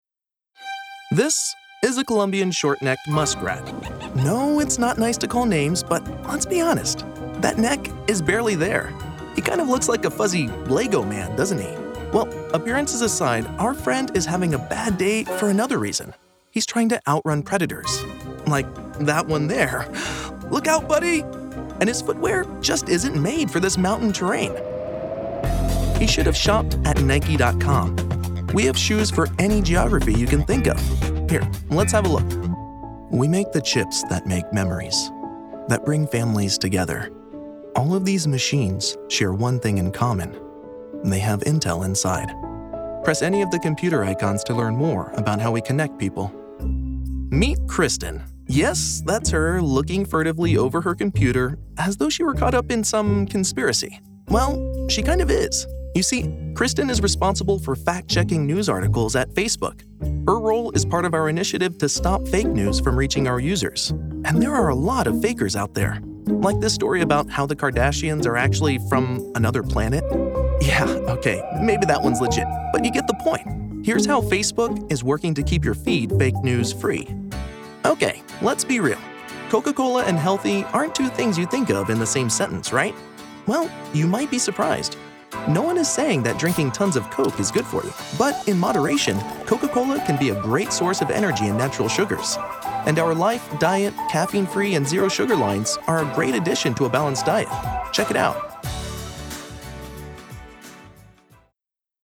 Explainer: Conversational, serious, friendly
Narrative, Presentation